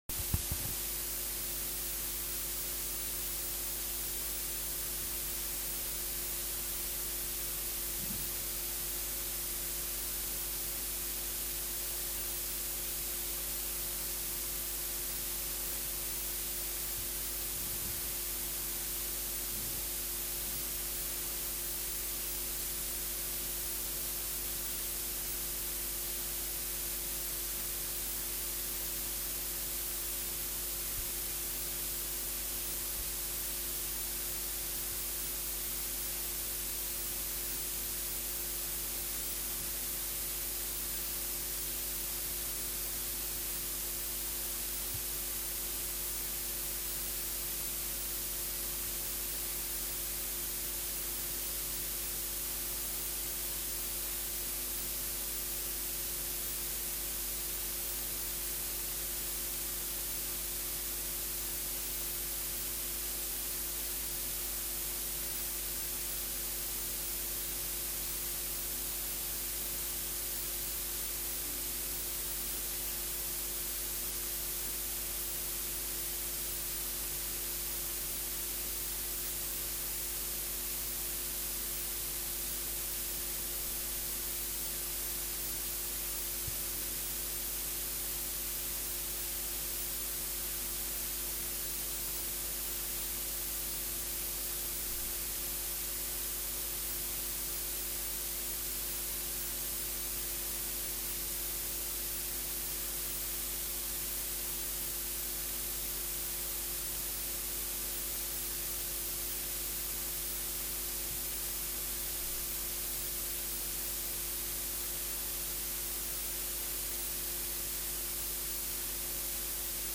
z posiedzenia Komisji Bezpieczeństwa w dniu 10.12.2018 r.
Załączony plik „Nagranie” zawiera pełny przebieg komisji w wersji fonicznej.